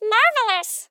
8 bits Elements
Voices Expressions Demo